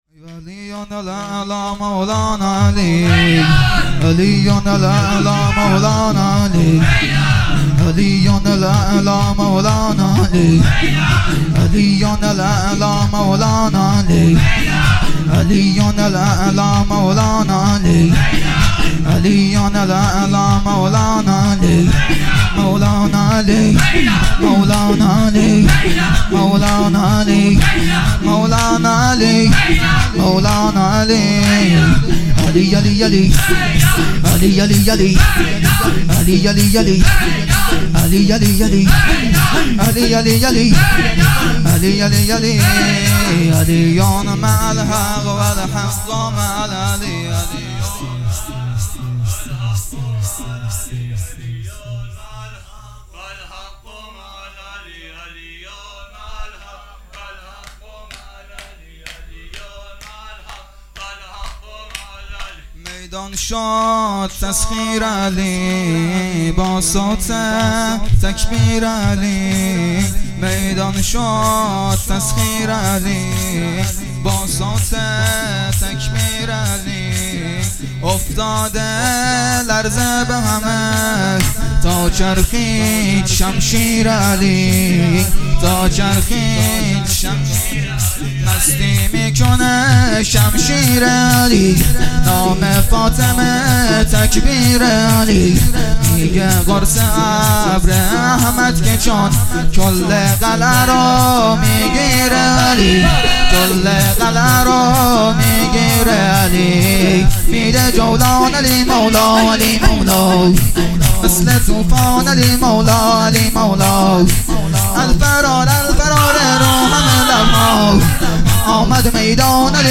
مراسم هفتگی ۴-۱۰-۹۹